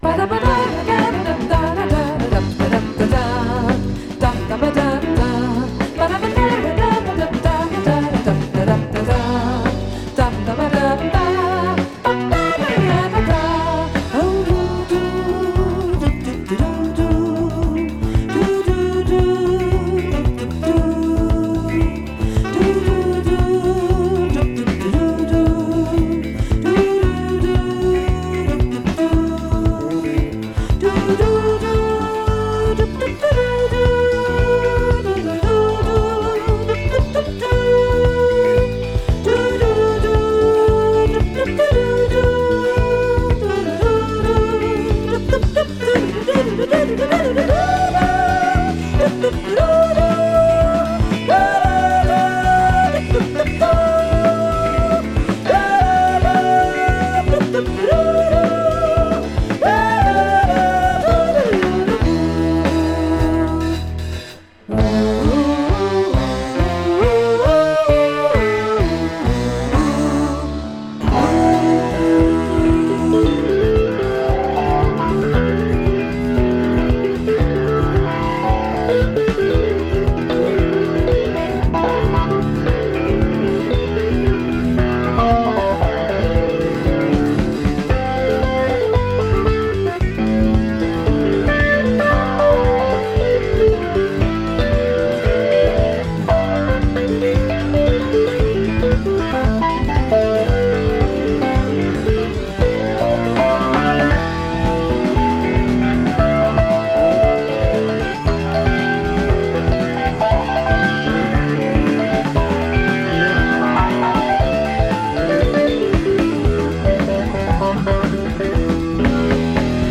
Format : LP